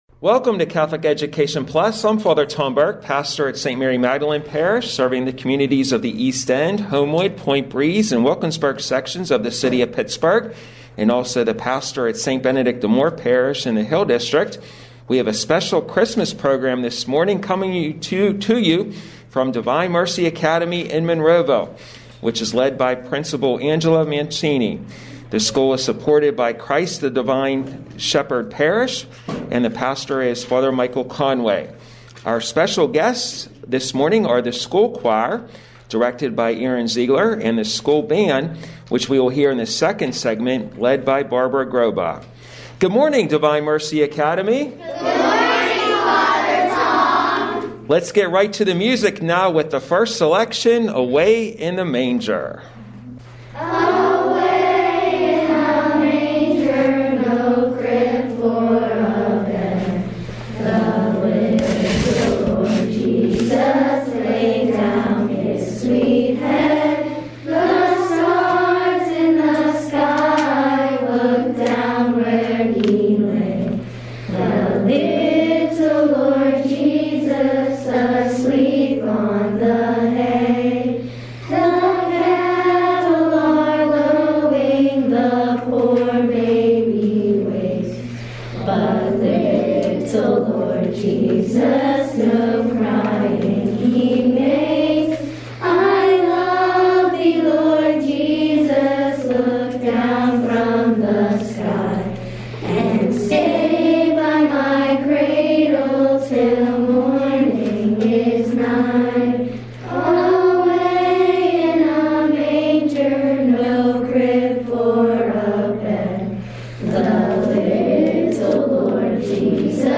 Catholic Education Plus Catholic Education Plus A special Christmas program from Divine Mercy Academy in Monroeville, featuring the school choir and band.